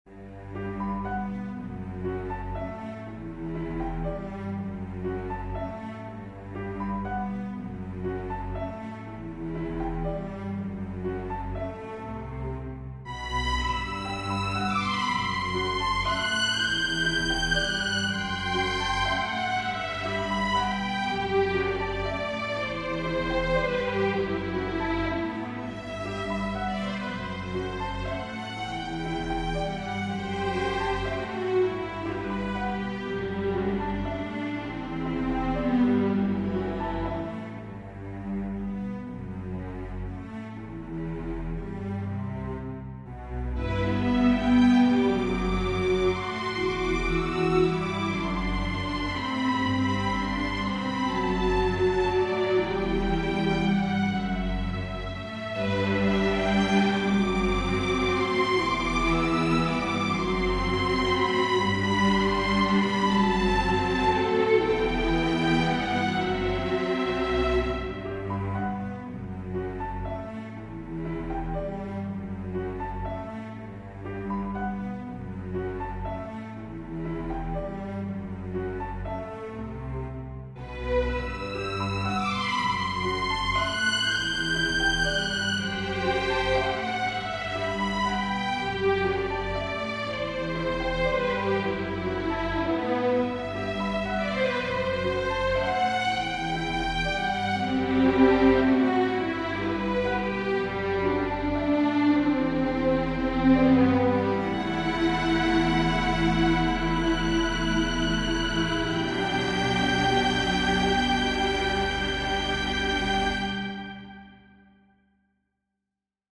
Piano Sextet
Piano Sextet 1:56 Classical